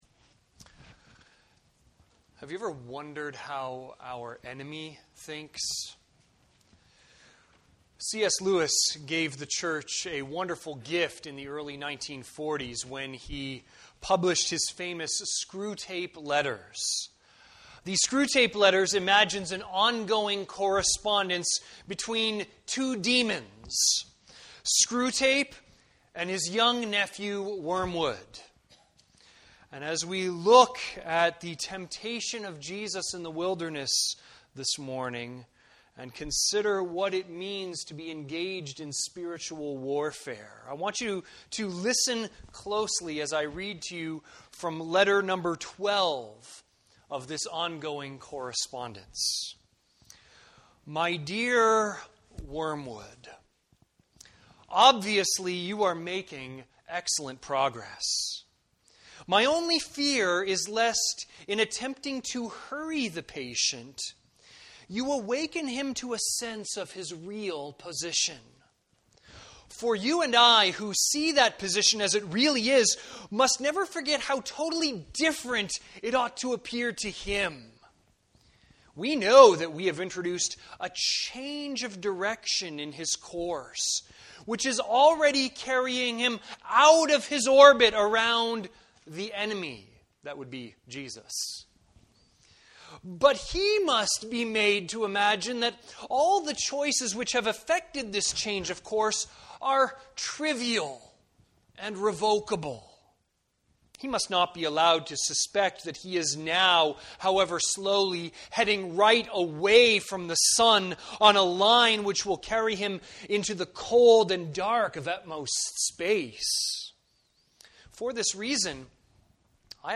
Sermons | Campbell Baptist Church
View the Sunday service.